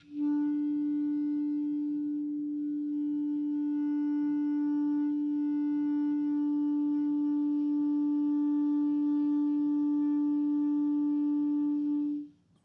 Clarinet
DCClar_susLong_D3_v1_rr1_sum.wav